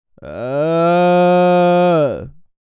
Male_Medium_Moan_01.wav